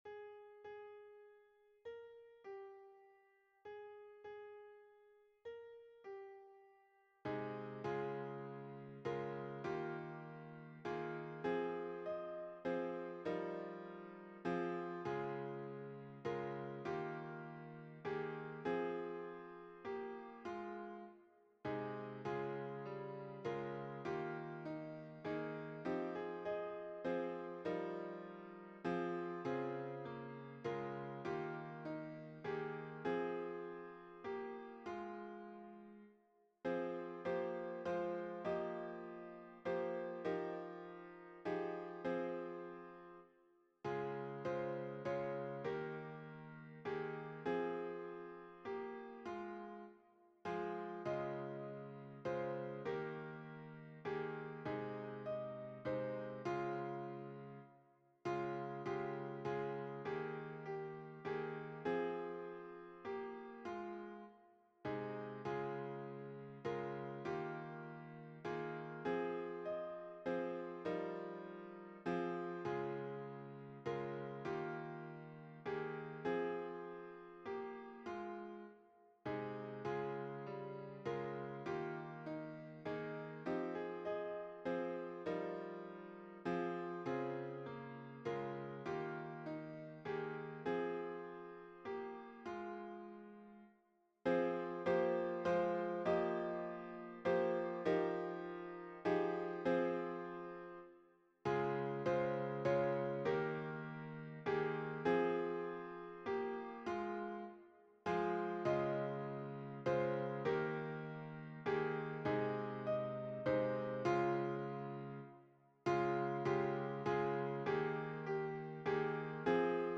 - Œuvre pour chœur à 4 voix mixtes (SATB) + 1 voix soliste
Solo Version Piano